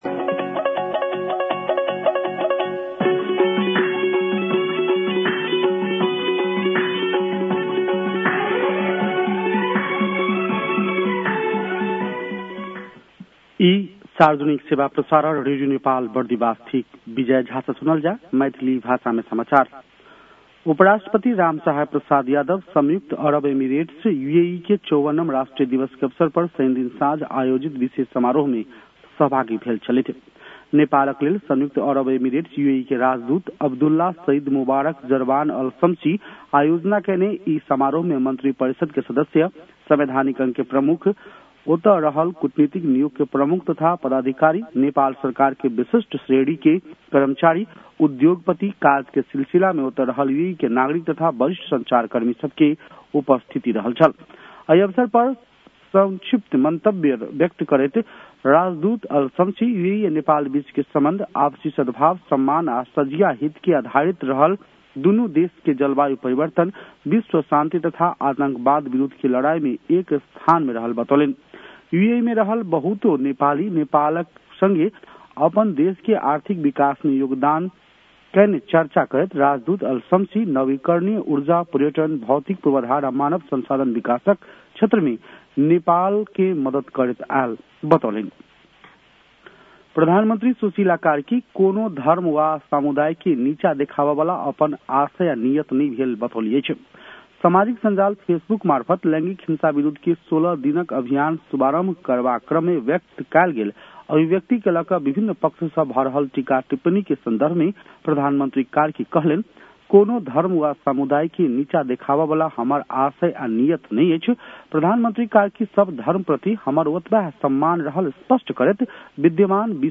मैथिली भाषामा समाचार : १४ मंसिर , २०८२
6.-pm-maithali-news-1-3.mp3